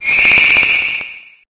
Cri_0824_EB.ogg